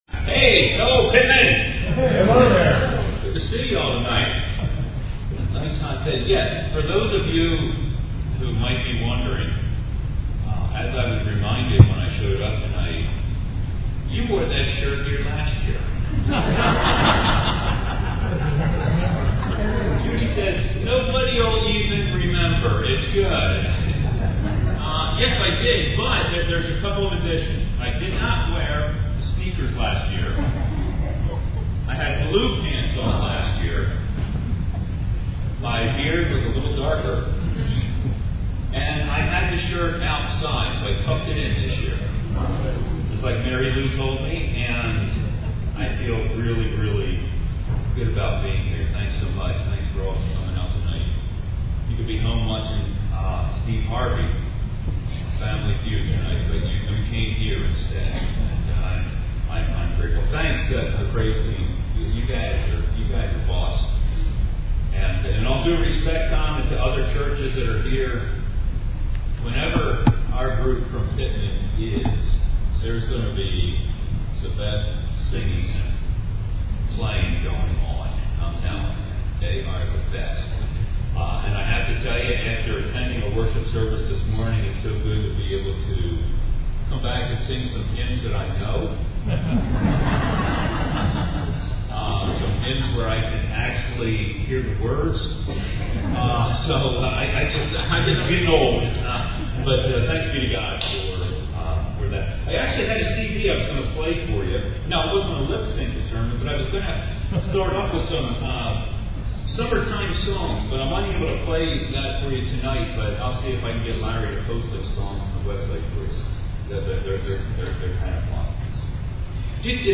PUMC Hosts the Camp Meeting Service
Scroll down to listen to a recording of the sermon and to follow through the summary.